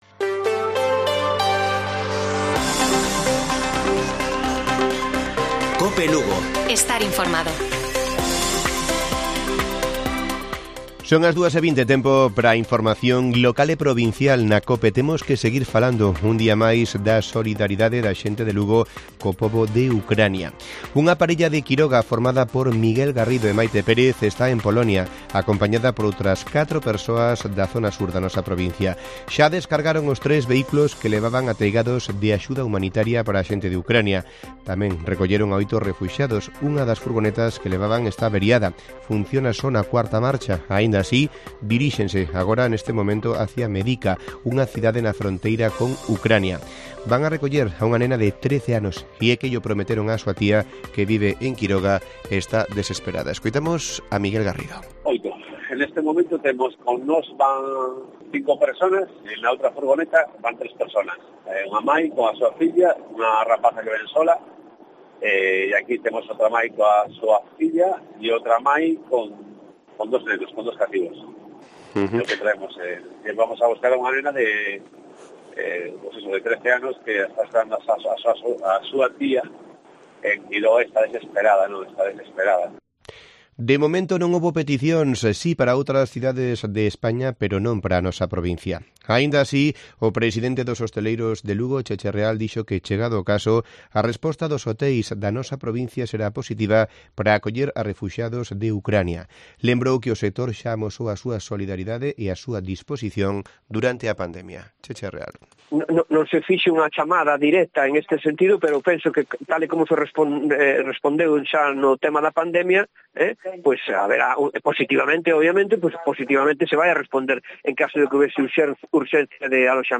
Informativo Mediodía de Cope Lugo. 08 de marzo. 14:20 horas